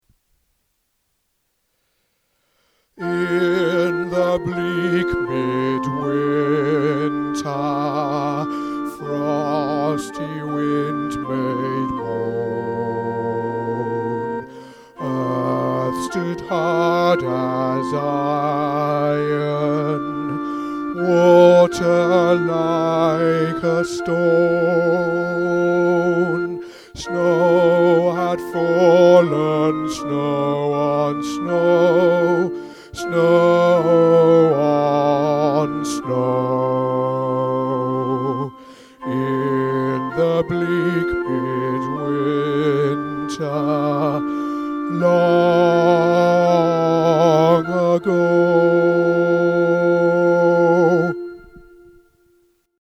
In The Bleak Midwinter – Alto | Ipswich Hospital Community Choir